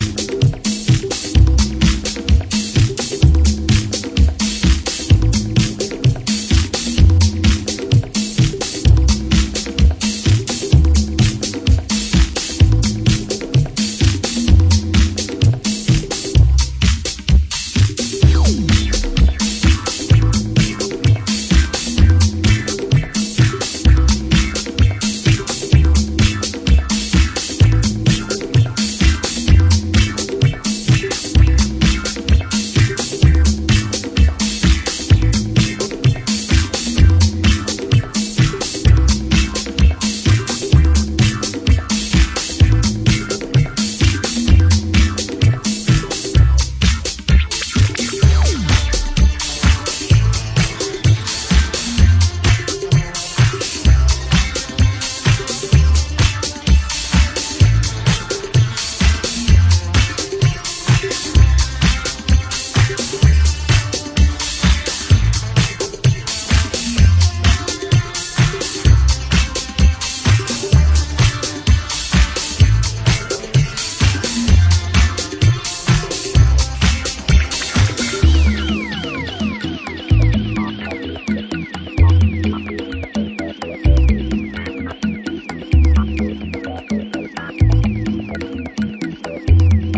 both are very 70ies Disco.
128bpm